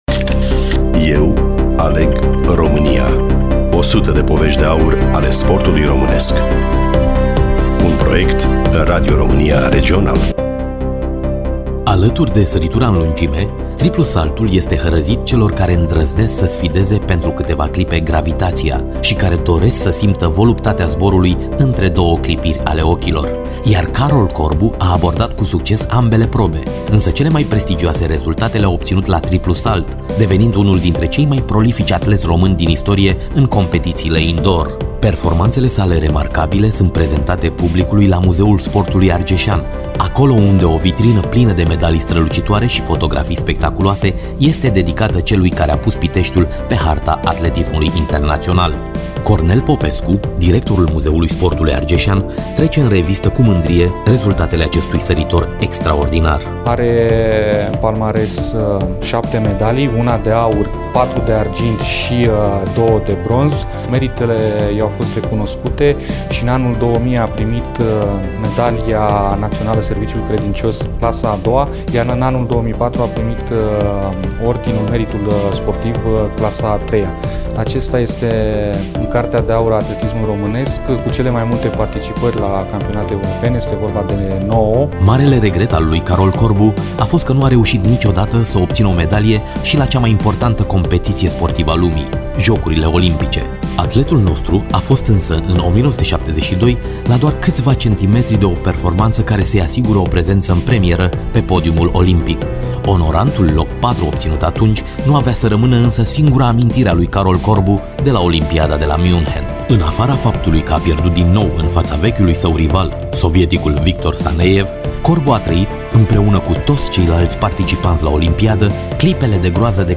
Studioul Radio Romania Oltenia Craiova